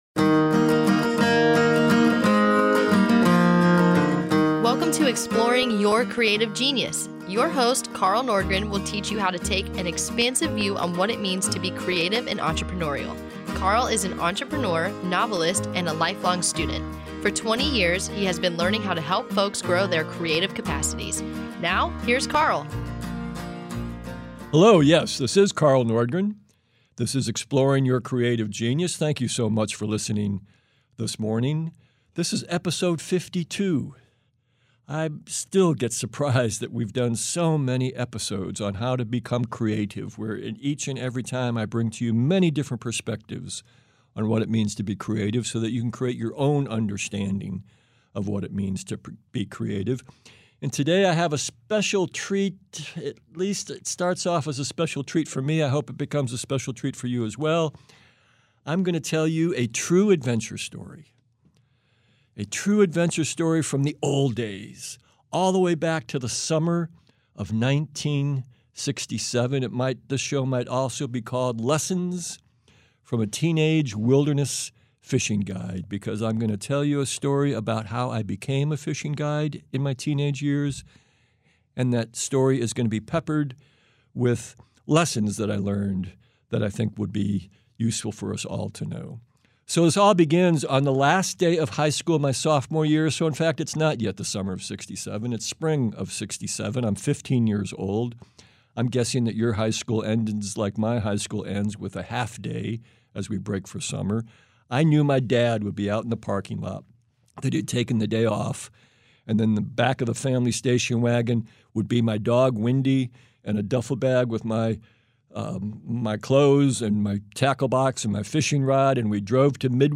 This week’s radio show was an adventure story about the summers in the 1960’s when I worked as a teenage fishing guide in NW Ontario; as I shared the story I highlighted some creatively entrepreneurial lessons I learned there.